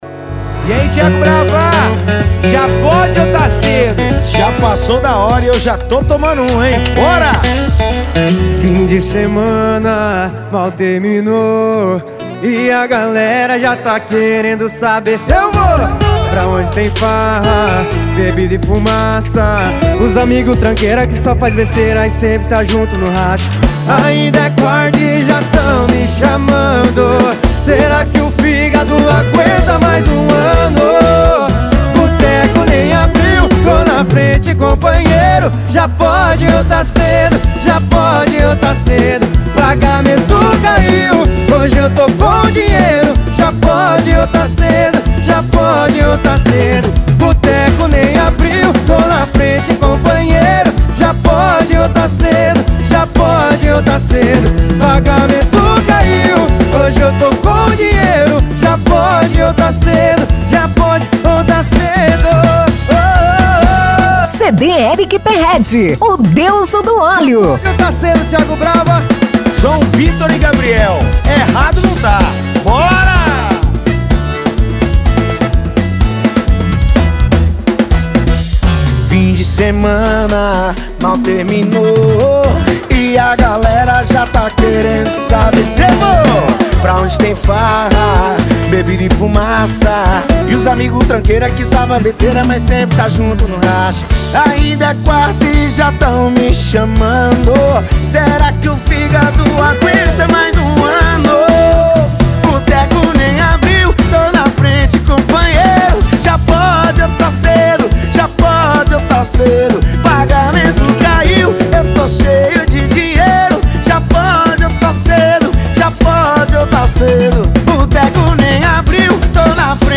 Sertanejo Universitário